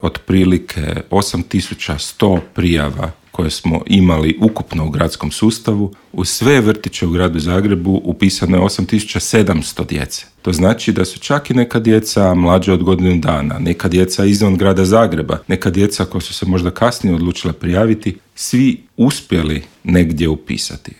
Bilježi se nastavak pada ukupnog broja učenika. U intervjuu tjedna sa ministrom obrazovanja Radovanom Fuchsom pričali smo o broju učenika i padu upisanih prvašića: